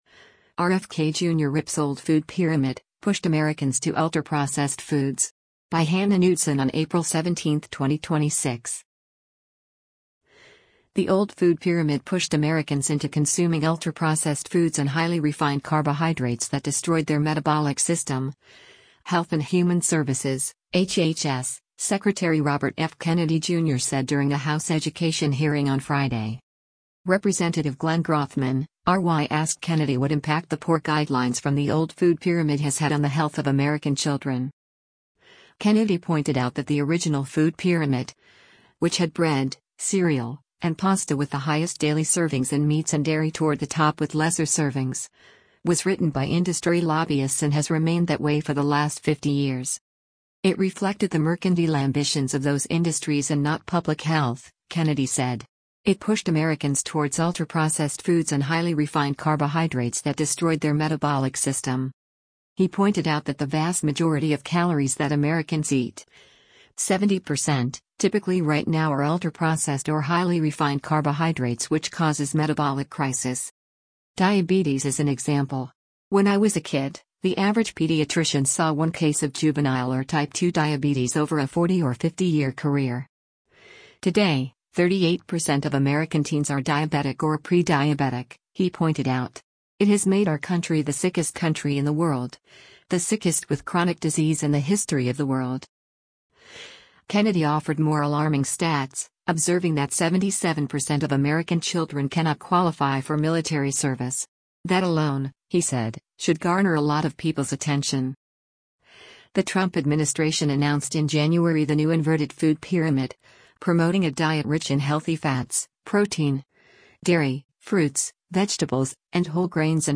The old food pyramid pushed Americans into consuming “ultra-processed foods and highly refined carbohydrates that destroyed their metabolic system,” Health and Human Services (HHS) Secretary Robert F. Kennedy Jr. said during a House education hearing on Friday.
Rep. Glenn Grothman (R-WI) asked Kennedy what impact the “poor” guidelines from the old food pyramid has had on the health of American children.